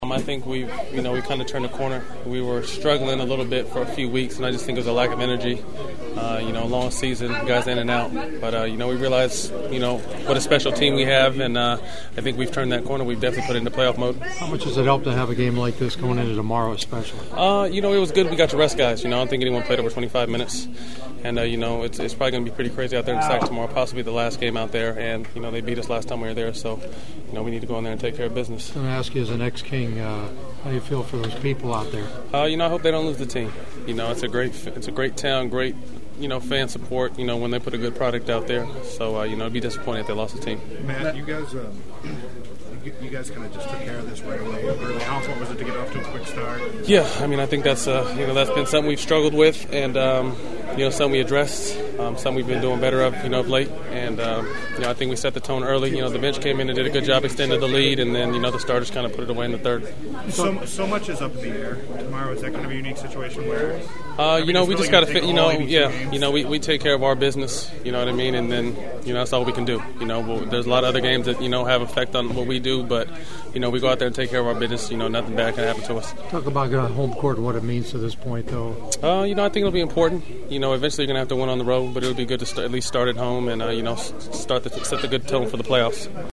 My other postgame locker room interviews…